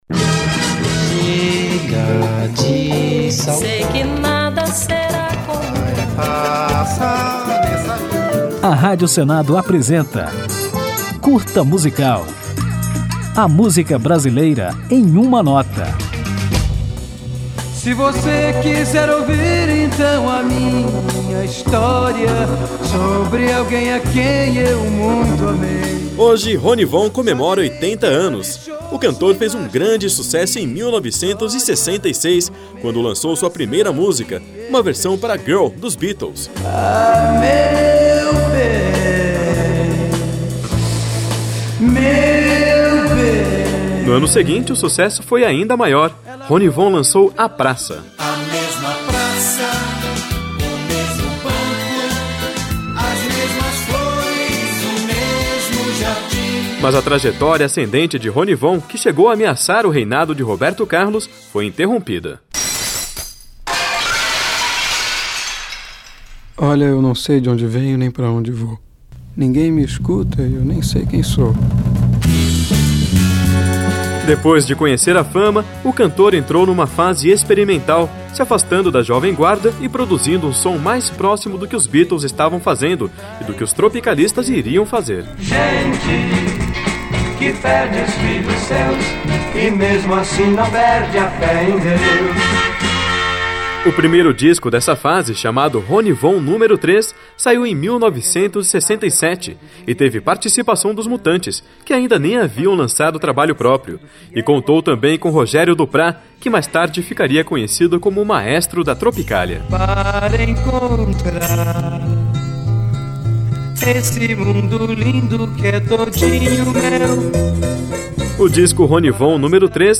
Para celebrar, o Curta Musical apresenta um episódio que mostra a fase obscura e psicodélica do artista, que o faz ser cultuado até os dias de hoje. A música escolhida para tocar ao final desta homenagem é Meu Novo Cantar, lançada em 1968, por Ronnie Von.